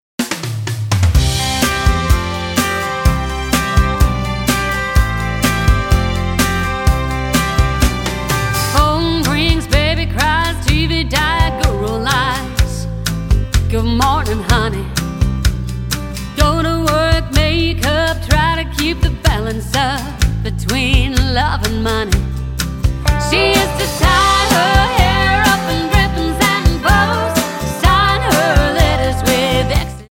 --> MP3 Demo abspielen...
Tonart:C Multifile (kein Sofortdownload.
Die besten Playbacks Instrumentals und Karaoke Versionen .